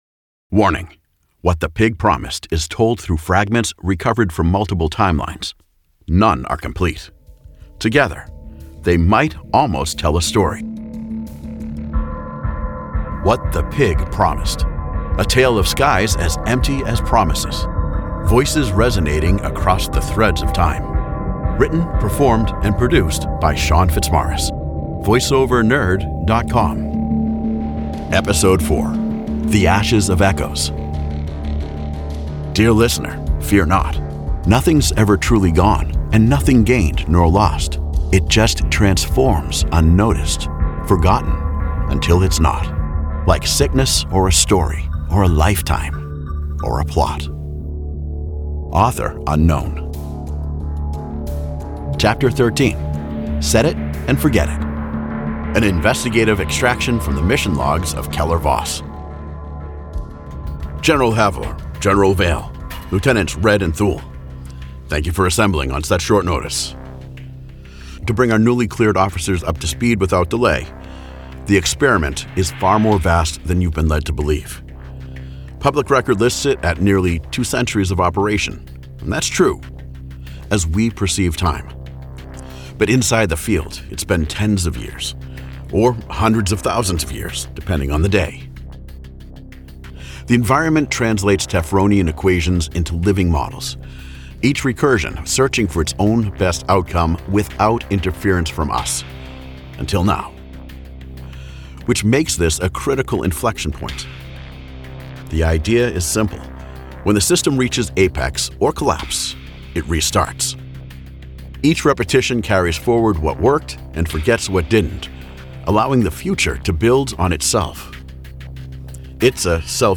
Serialized Audio Drama